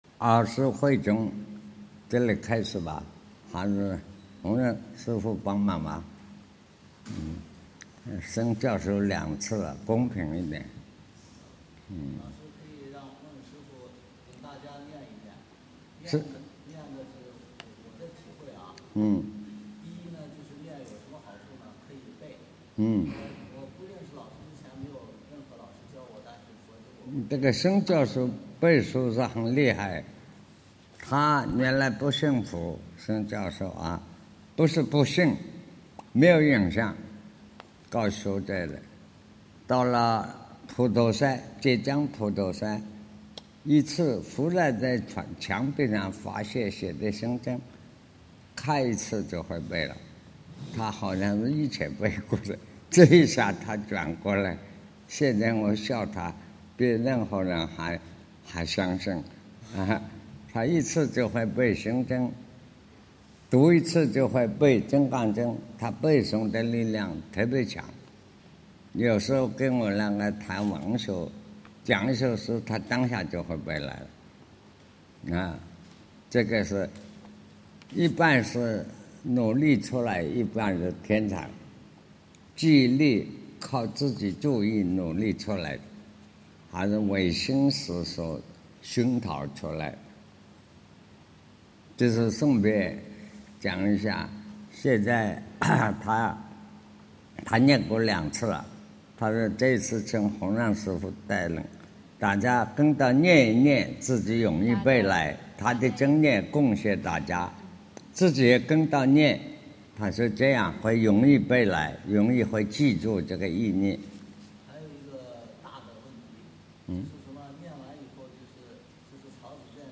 识的认识与求证 南怀瑾先生讲大乘显识经(7)